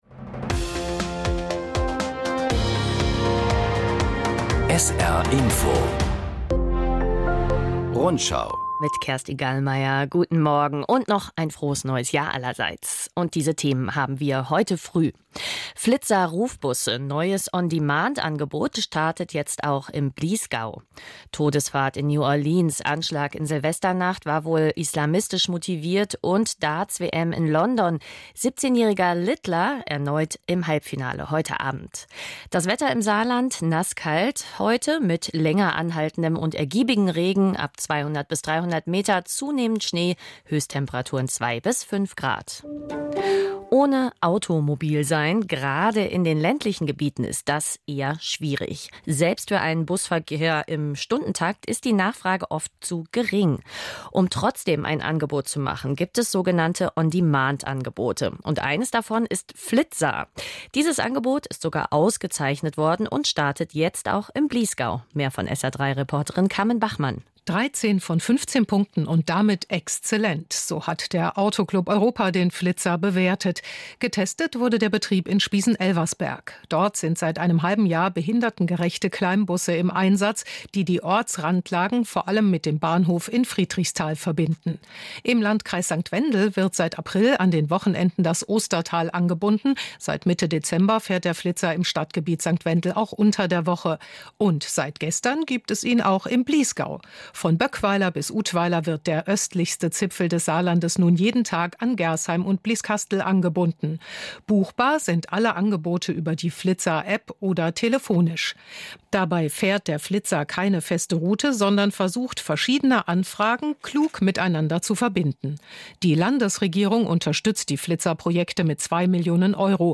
Zehn Minuten Information aus aller Welt und aus der Region … continue reading 5 επεισόδια # Nachrichten